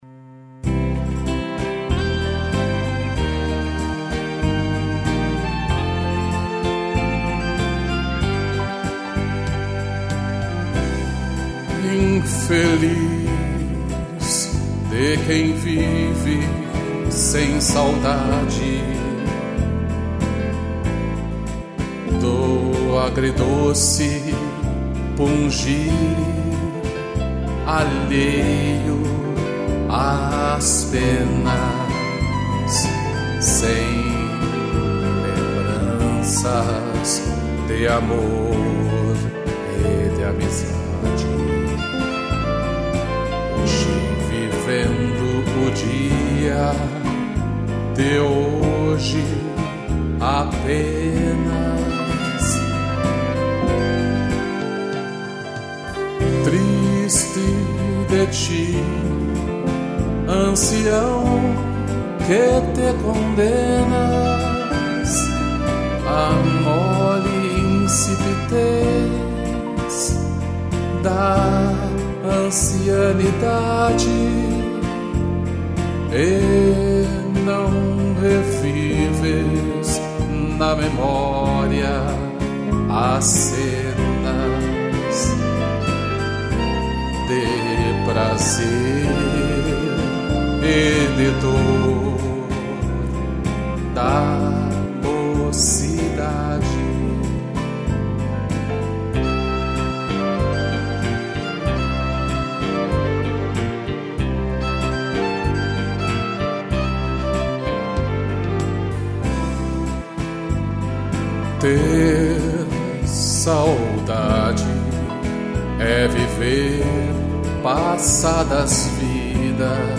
piano, violino e cello